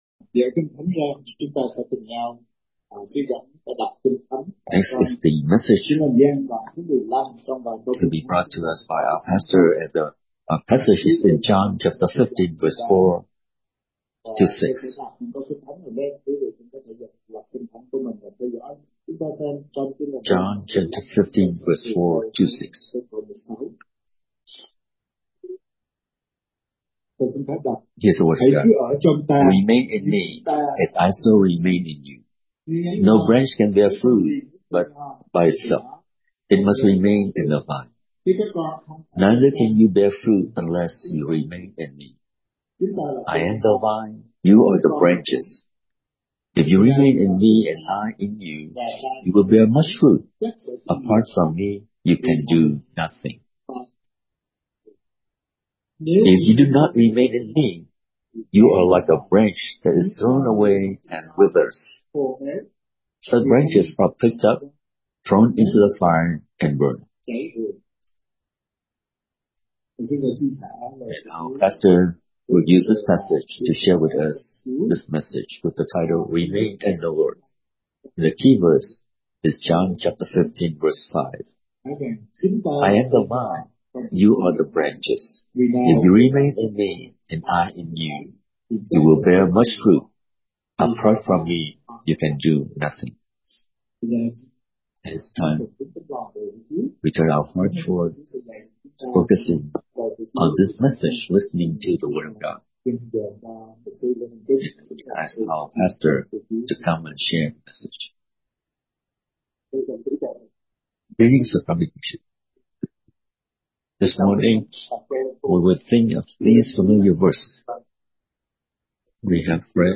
Thờ Phượng Chúa Nhật Ngày 4 Tháng 1, 2026: Được Ở Trong Chúa – HỘI THÁNH TRUYỀN GIÁO BÁP TÍT